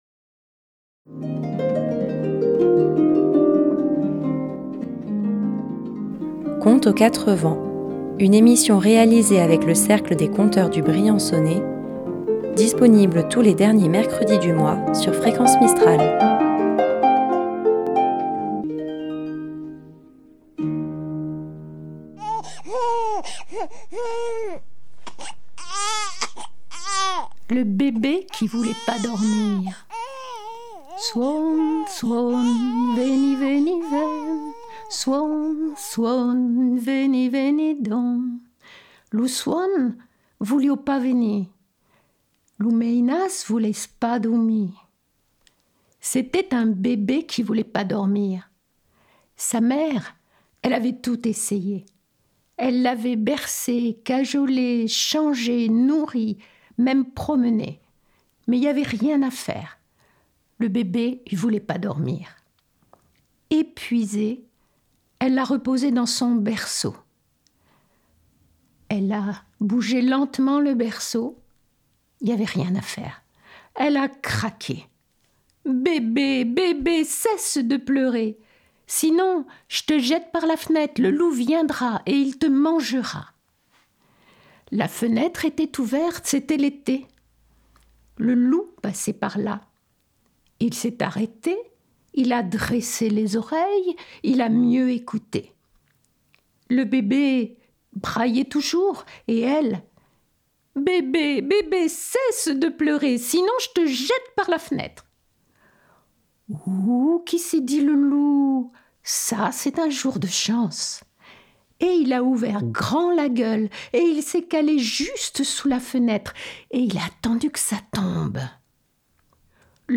Tous les derniers mercredi du mois, à 18h10, retrouvez le Cercle des conteurs du Briançonnais pour une balade rêveuse.